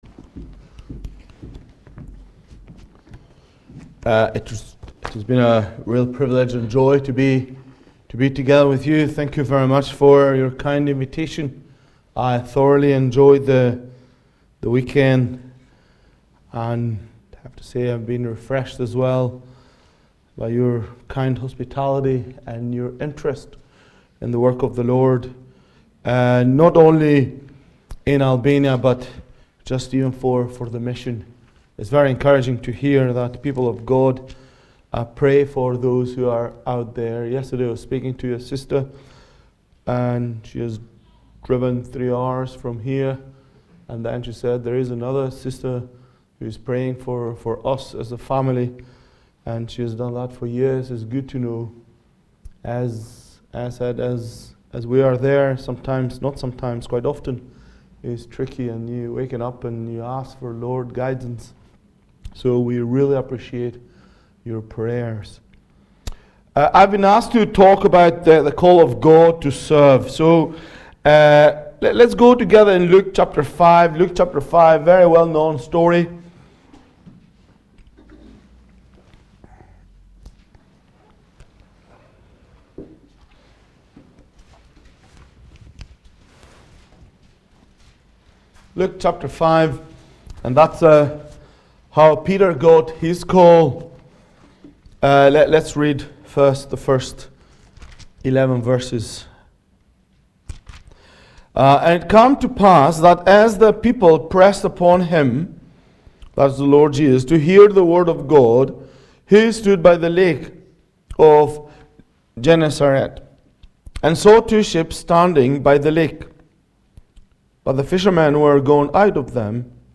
Passage: Luke 5:1-11 Service Type: Ministry